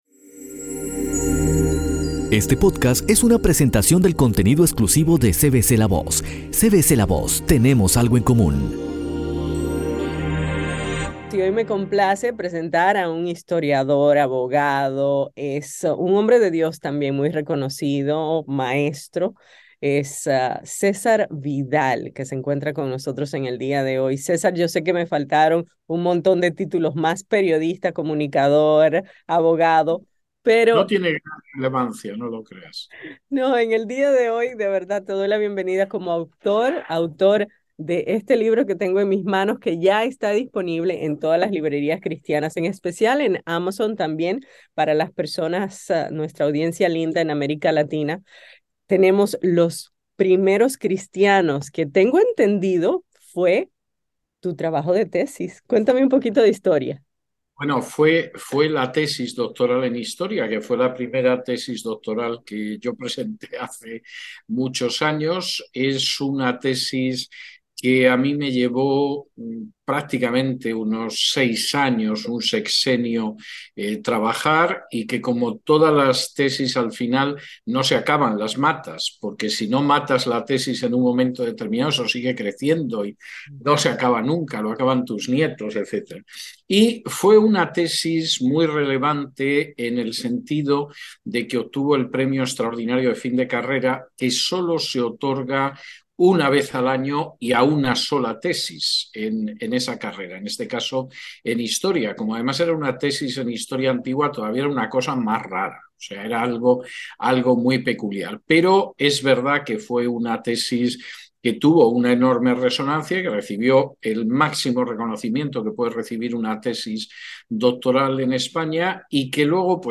En una entrevista exclusiva el escritor español Cesar Vidal nos habla sobre los primeros cristianos.